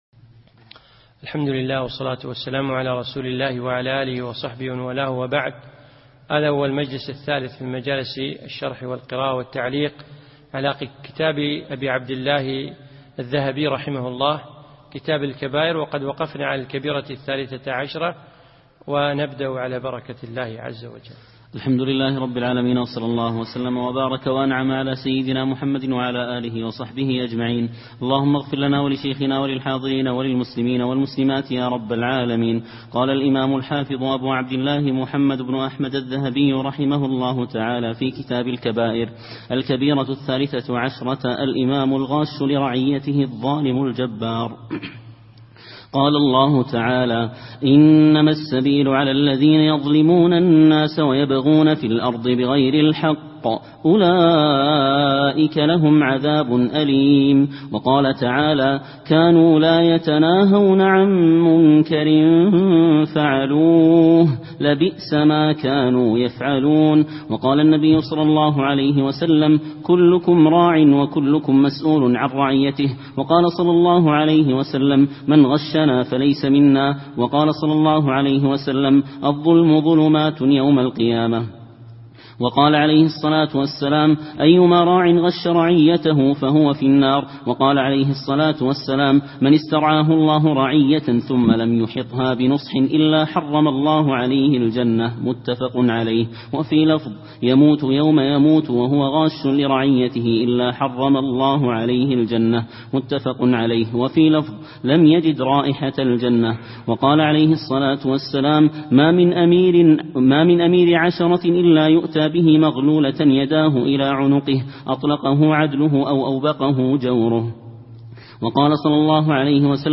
يوم الثلاثاء 18 ذو العقدة 1436هـ 1 9 2015م في مسجد عائشة المحري المسايل